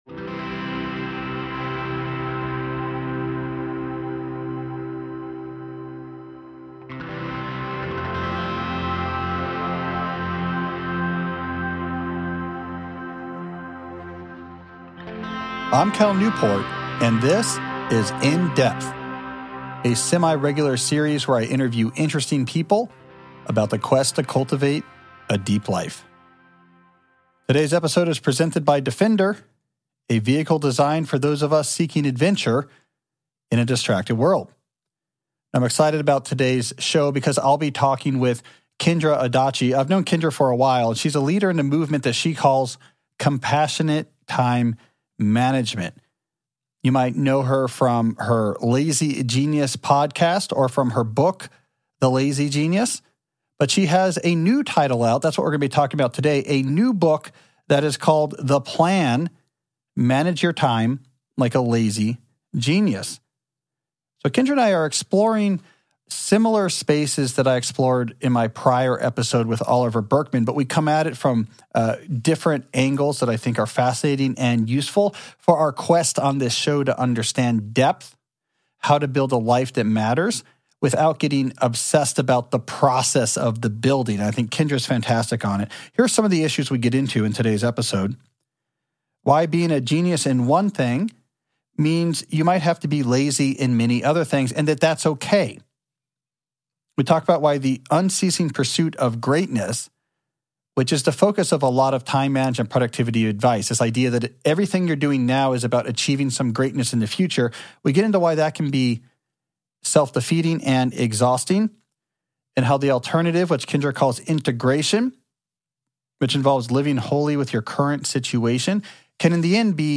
I'm Cal Newport, and this is In-depth, a semi-regular series where I interview interesting people about the quest to cultivate a deep life.